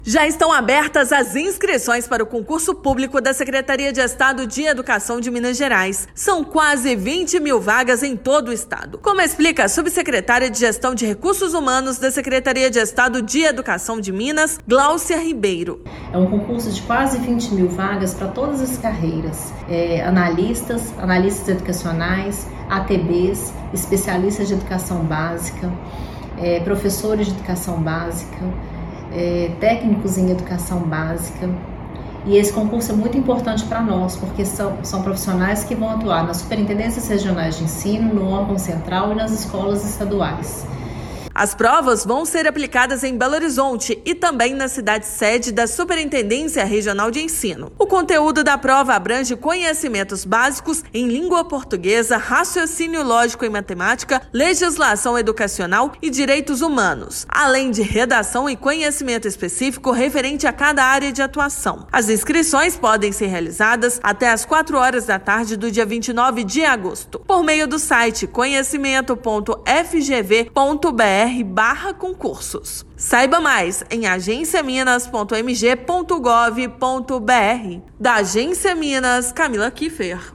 [RÁDIO] Já estão abertas as inscrições para o concurso público da Secretaria de Educação de Minas Gerais
São quase 20 mil vagas para atuação em unidades escolares da rede estadual e órgãos vinculados. Ouça matéria de rádio.